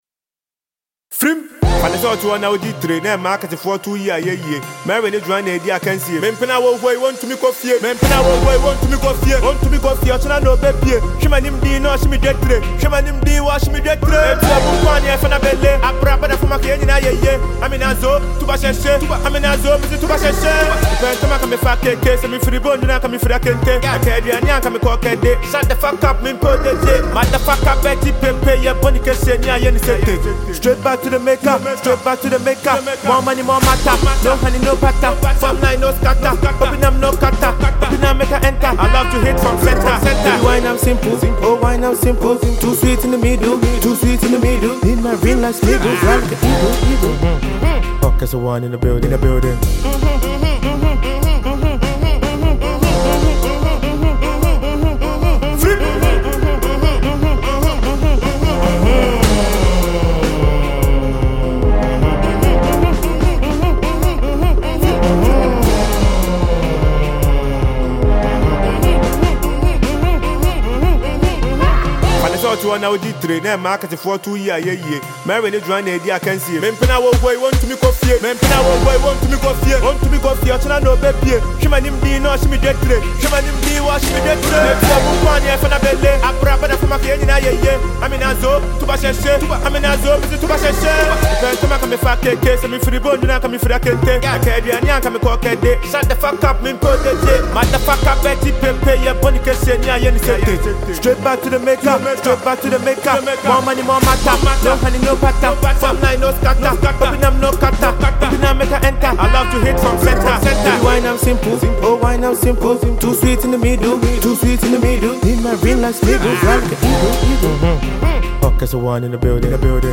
Ghanaian rapper and songwriter